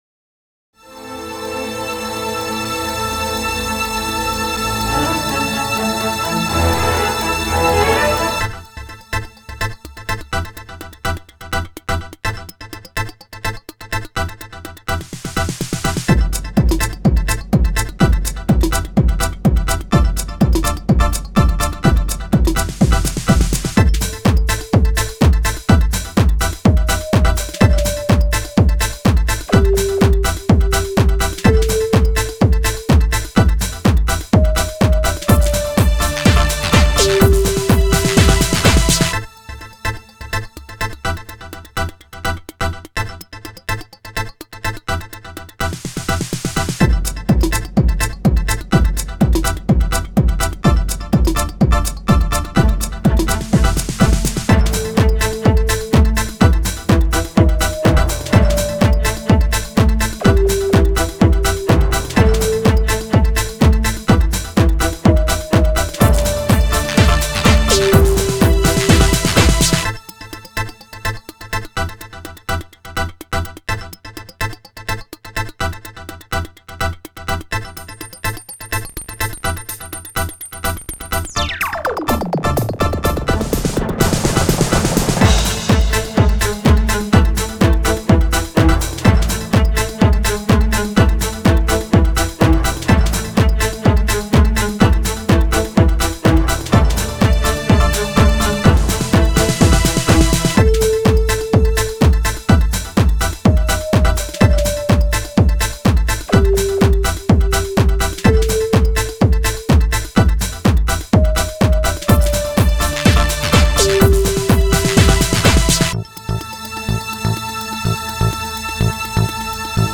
Genres: Rock / Alternative / Indy
(no lyrics)
REMASTERED in 2020 with proper spectral balancing
Originally recorded in 2002 using Sony Acid.